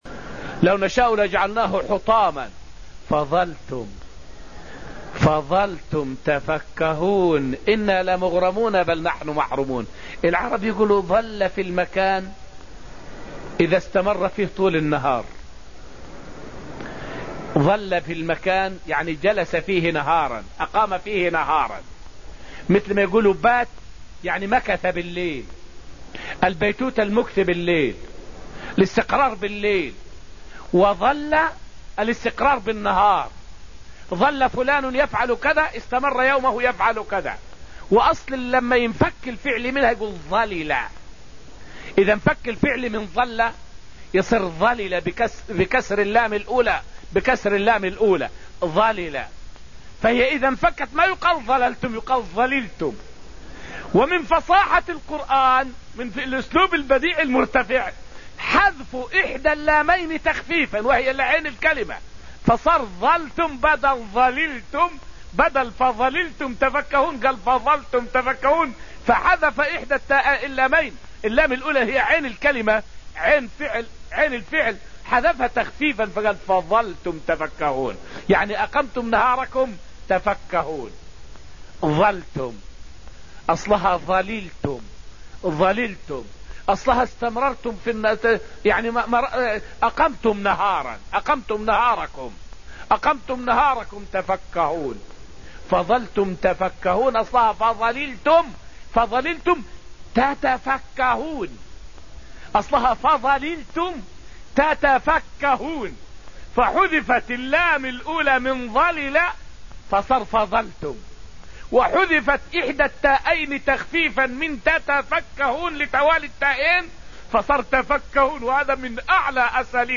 فائدة من الدرس السابع من دروس تفسير سورة الواقعة والتي ألقيت في المسجد النبوي الشريف حول الأسلوب البلاغي في قوله: {فَظَلْتُمْ تفكّهون}.